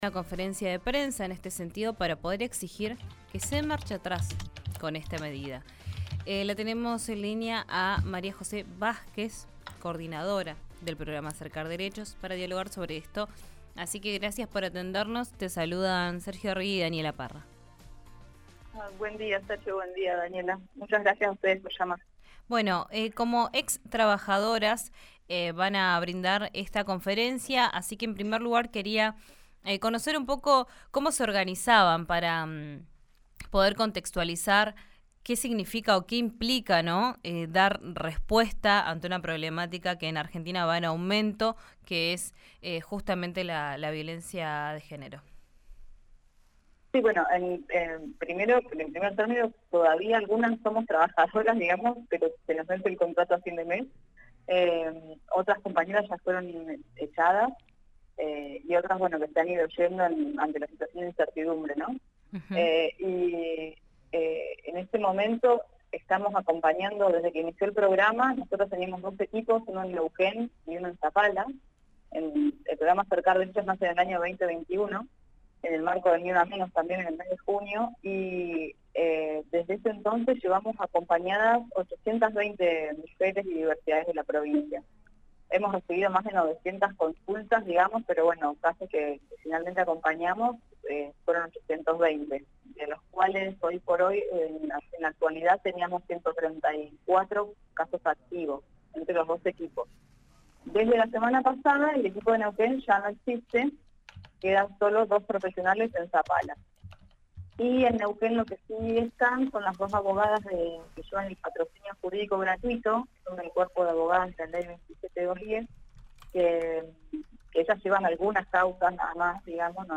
En diálogo con RIO NEGRO RADIO